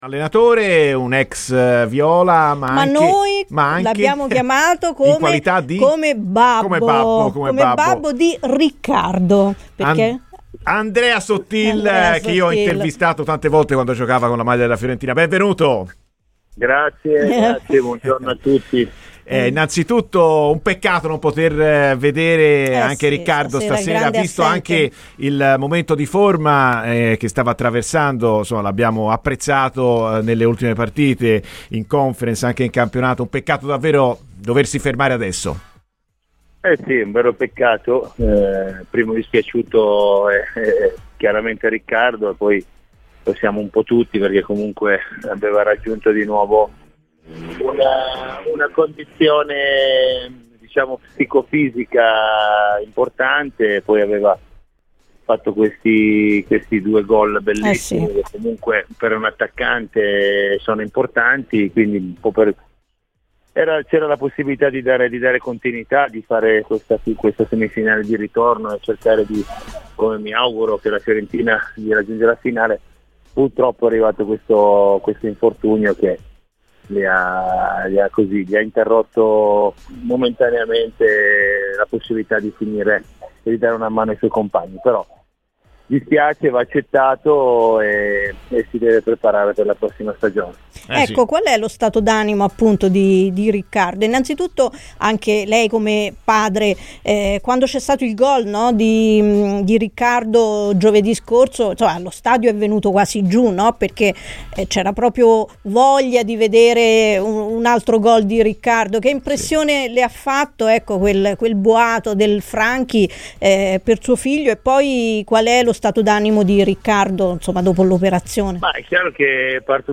ha parlato così a 'Viola amore mio' in diretta su Radio Firenzeviola